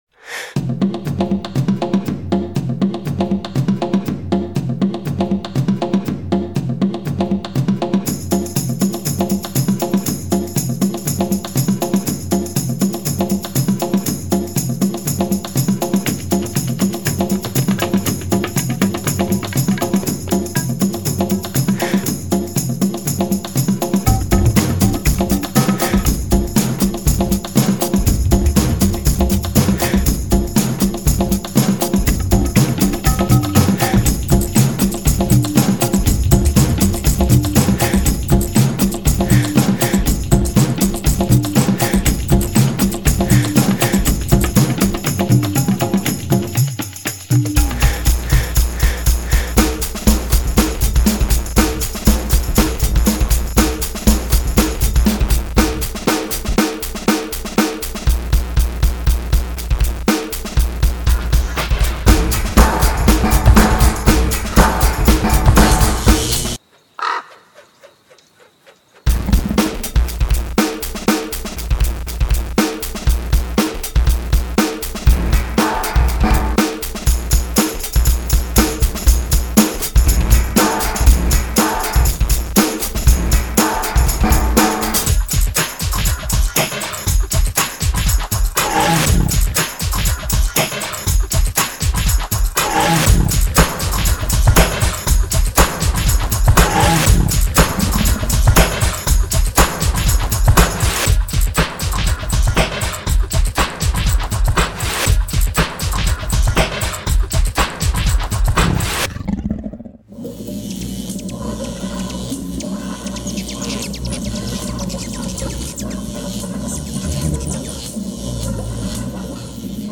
Built with Actionscript 3.0. "Nagual Hummingbird" 2007 ProTools Mp3 constructed entirely of sampled loops.